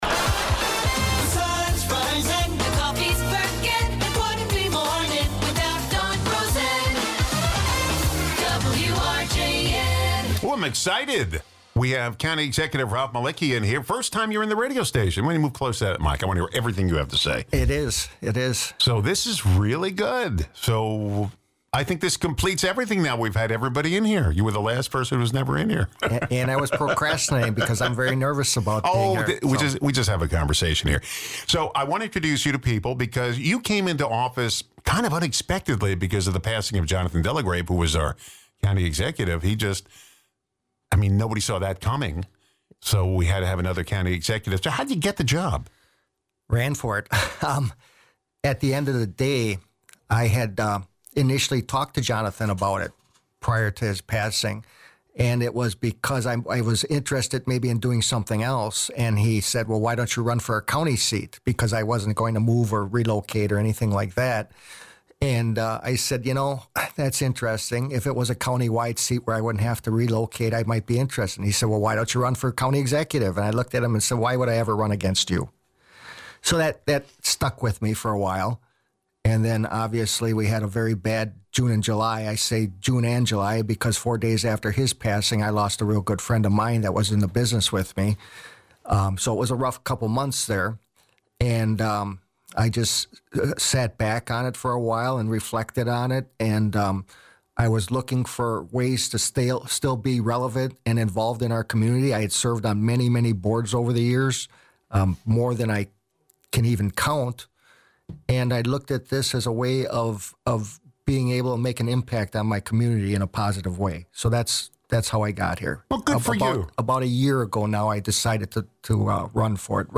Racine County Executive Ralph Malicki introduces himself to the WRJN audience and talks about his accomplishments and plans for the county.